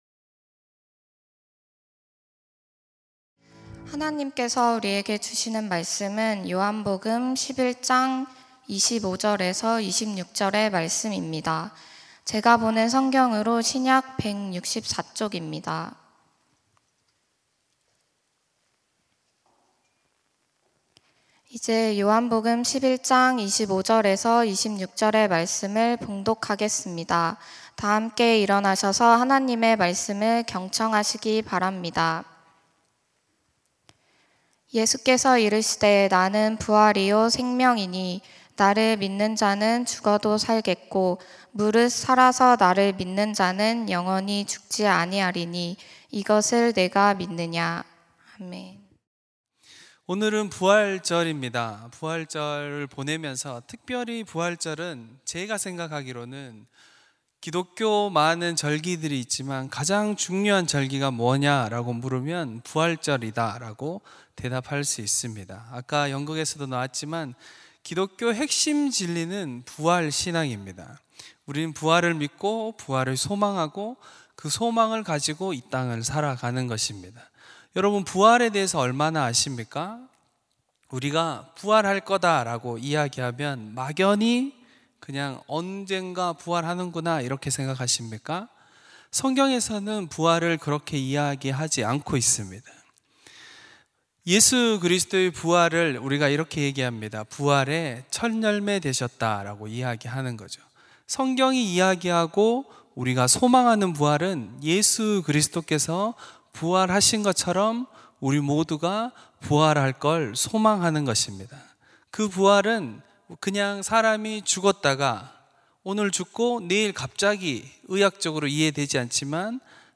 주일 청년예배 - 예수 부활 생명